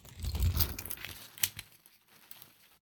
umbrella2.wav